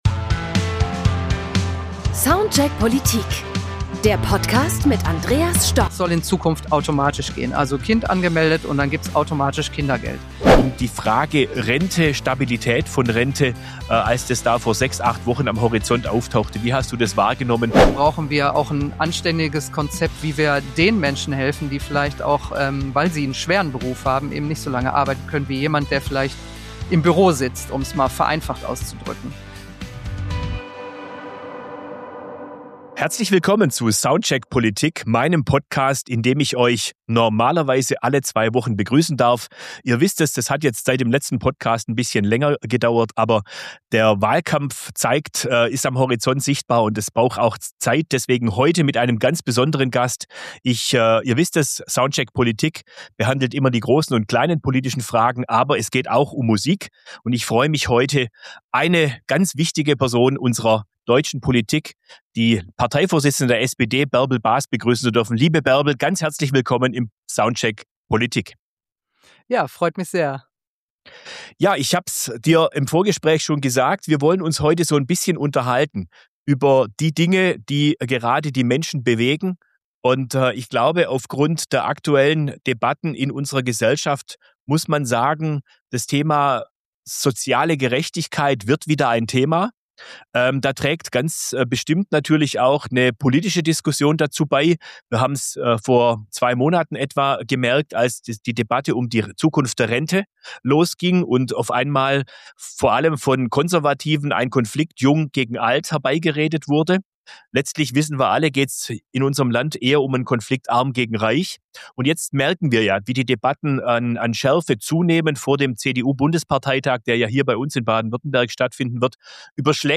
In dieser Folge von Soundcheck Politik spricht SPD-Fraktionsvorsitzender im Landtag von Baden-Württemberg, Andreas Stoch mit der SPD-Parteivorsitzenden Bärbel Bas, die derzeit auch Bundesministerin für Arbeit und Soziales ist.